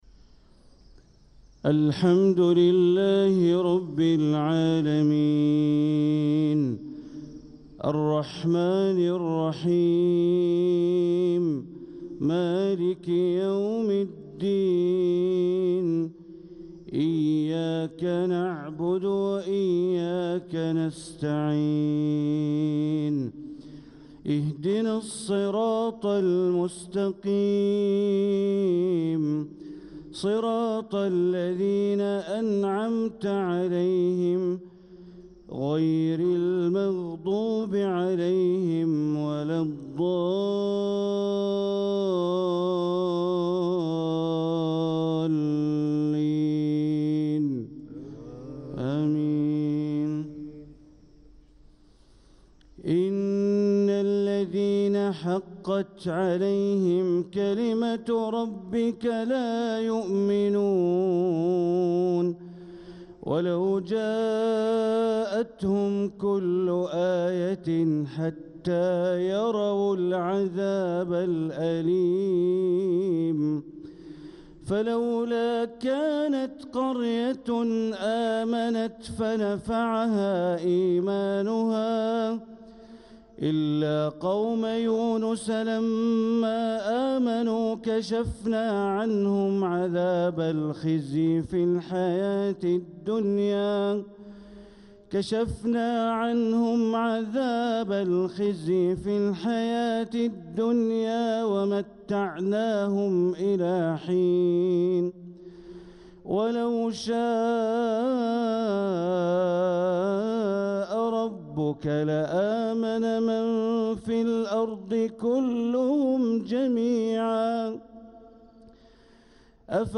صلاة الفجر للقارئ بندر بليلة 21 ربيع الآخر 1446 هـ